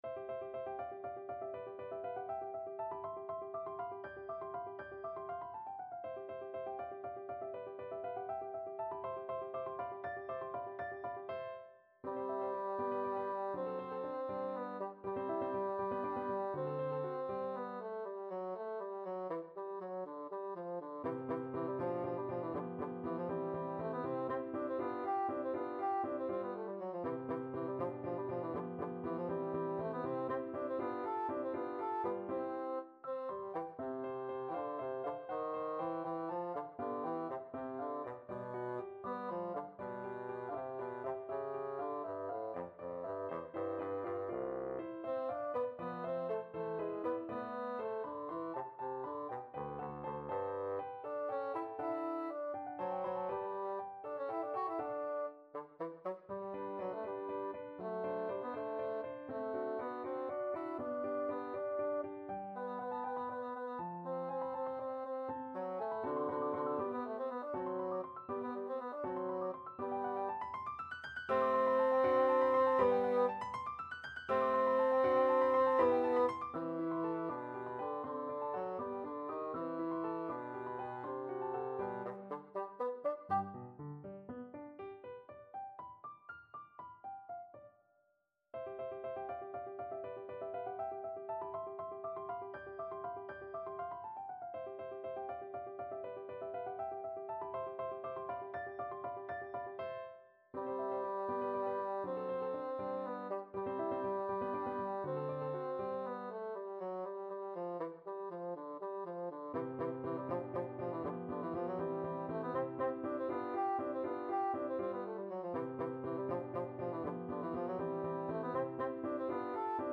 Bassoon version
~ = 100 Allegro (View more music marked Allegro)
6/8 (View more 6/8 Music)
Classical (View more Classical Bassoon Music)